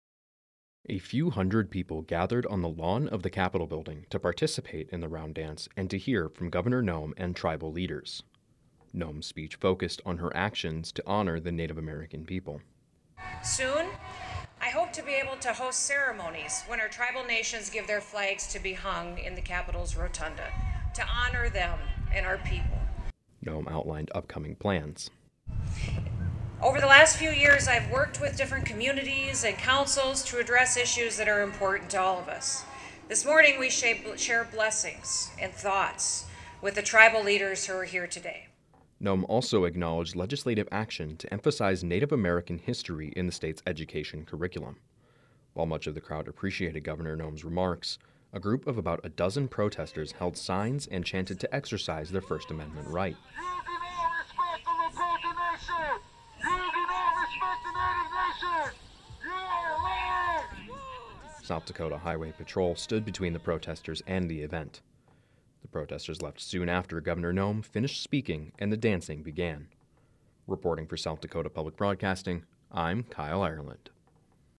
A few hundred people gathered on the lawn of the Capitol Building to participate in the round dance and to hear from Governor Noem and tribal leaders.
While much of the crowd appreciated Governor Noem’s remarks, a group of about a dozen protestors held signs and chanted to exercise their first amendment right.